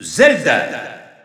Announcer pronouncing Zelda's name in French.
Zelda_French_Announcer_SSBU.wav